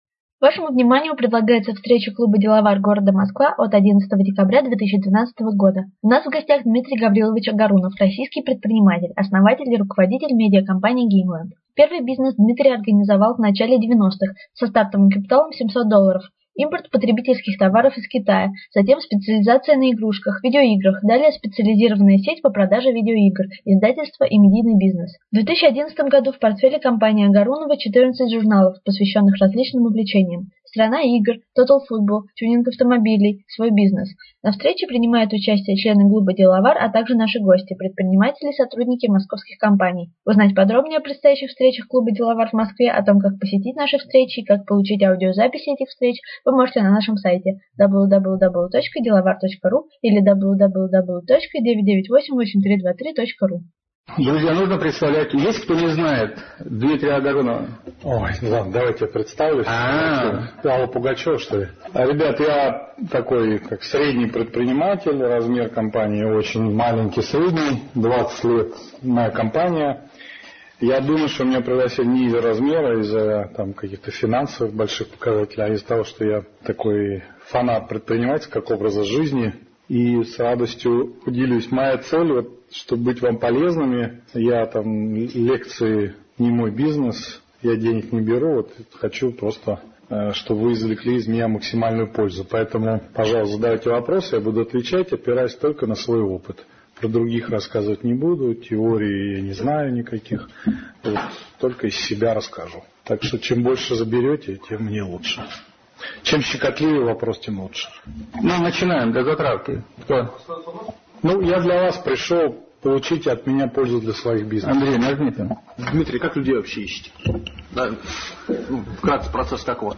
11 декабря 2012 года состоялась последняя деловая встреча клуба предпринимателей «Деловар» в 2012 году.